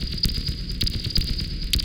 Index of /musicradar/rhythmic-inspiration-samples/130bpm
RI_ArpegiFex_130-02.wav